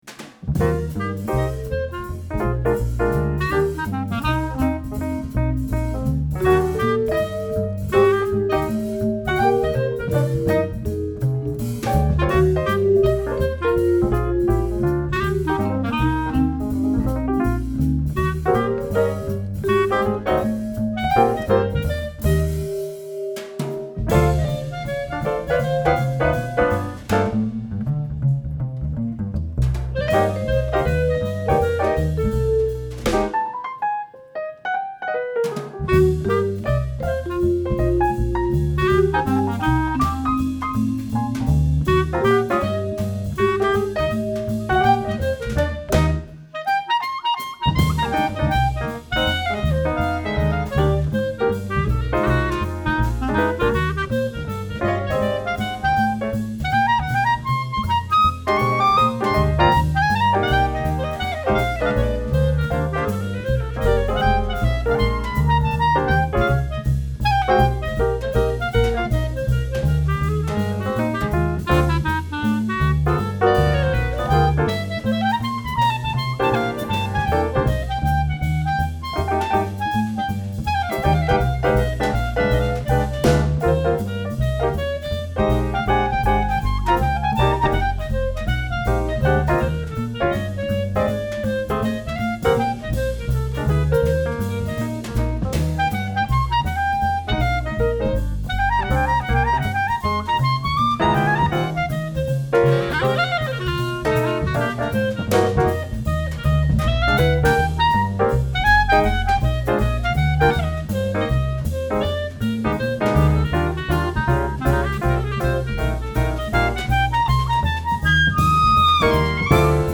Sala Concerti SMP di Testaccio Roma Riduci
vibrafono
piano
contrabbasso
batteria
clarinetto